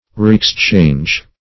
reexchange - definition of reexchange - synonyms, pronunciation, spelling from Free Dictionary
Reexchange \Re`ex*change"\ (r?`?ks*ch?nj"), v. t.